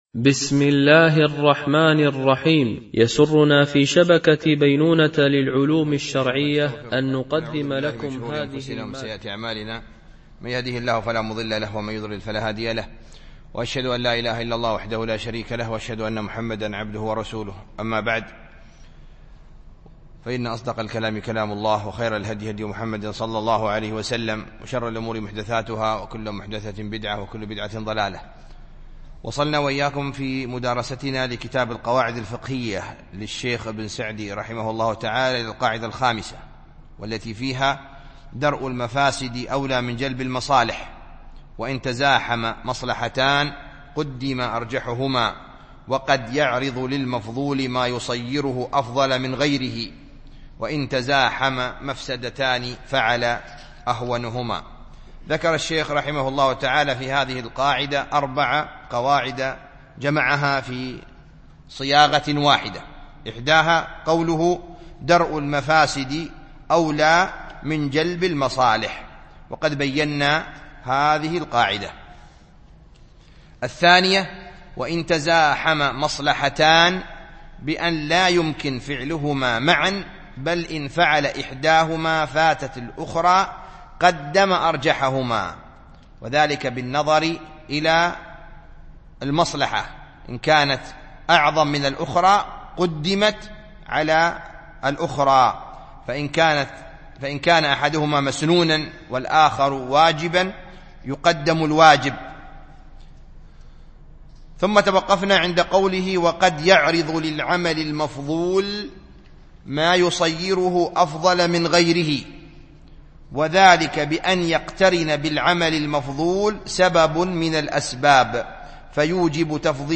التعليق على القواعد الفقهية ـ الدرس الثامن